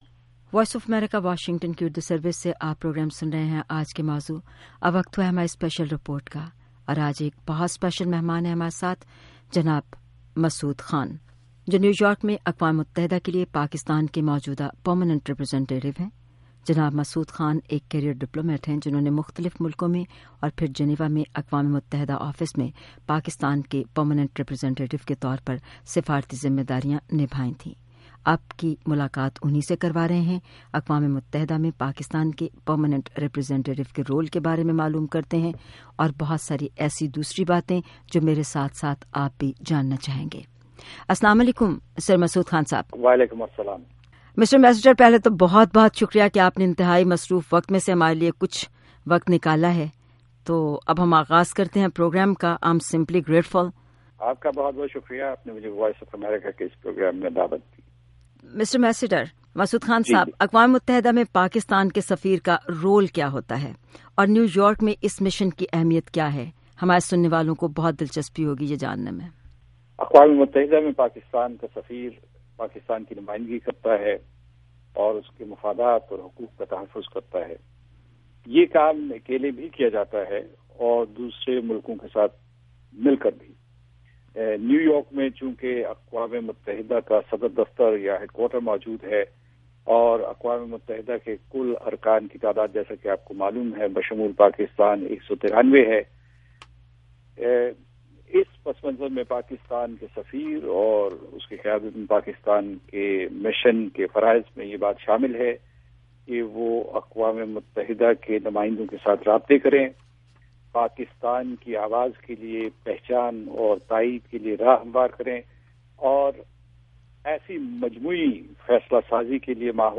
اقوام متحدہ میں پاکستانی مندوب، مسعود خان کا انٹرویو